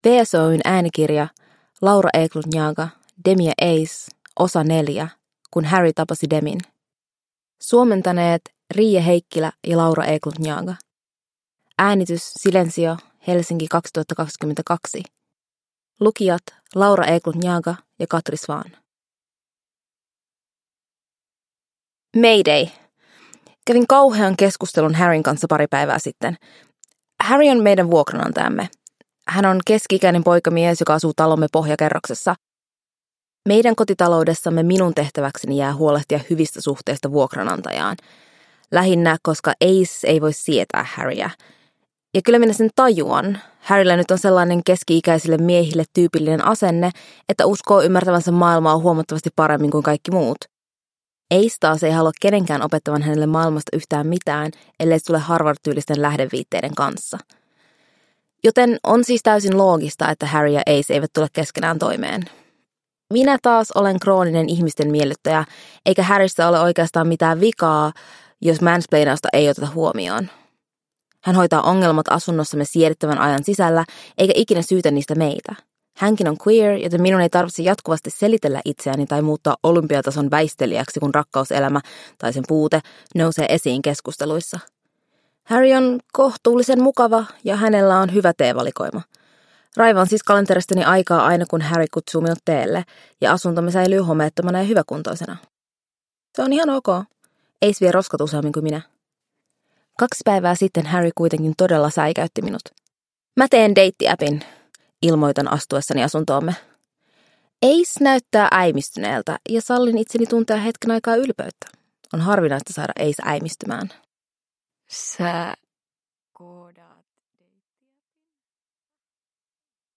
Demi & Ace 4: Kun Harry tapasi Demin – Ljudbok – Laddas ner